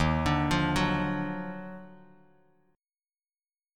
D#mM9 chord